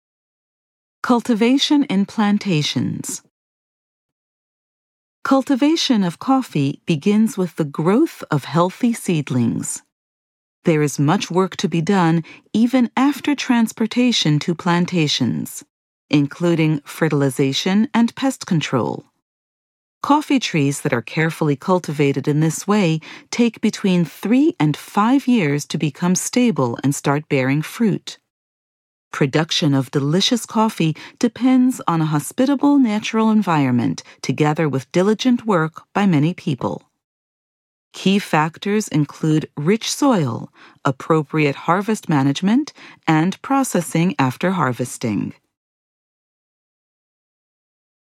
Audio exhibition guide